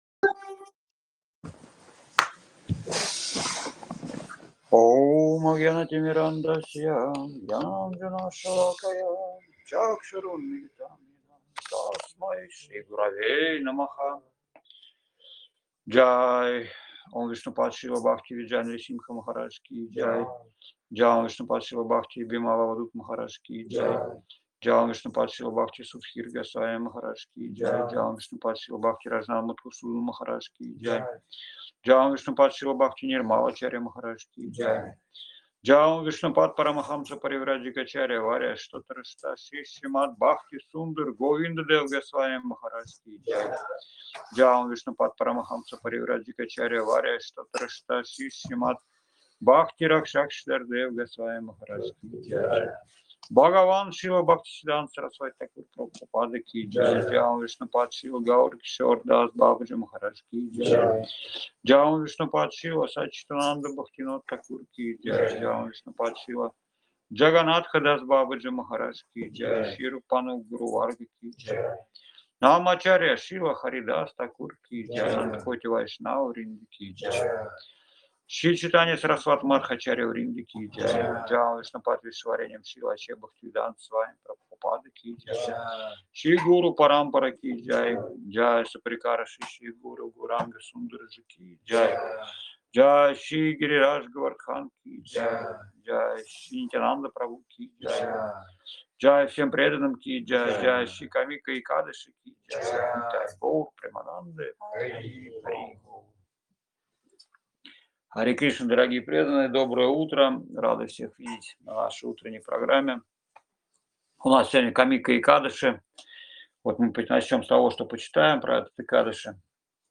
Узунджа, Крым
Лекции полностью